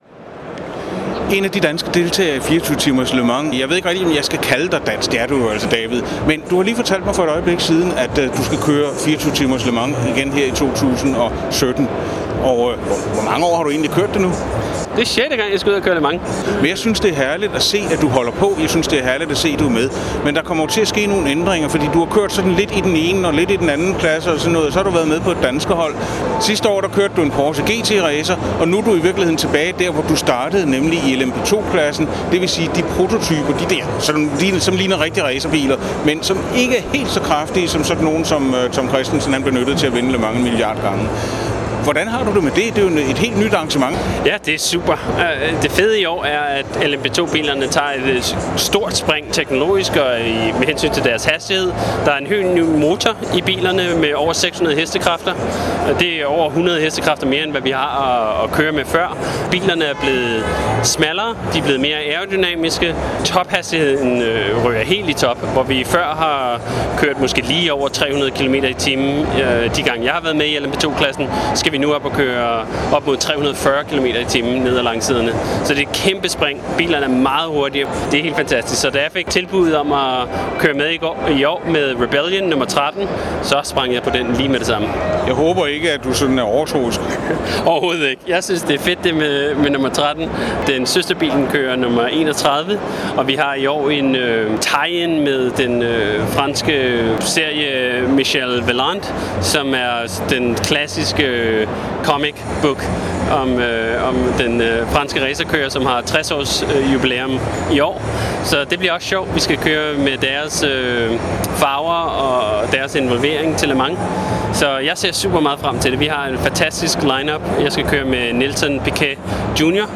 2017 Le Mans David Heinemeier Hansson preview interview.mp3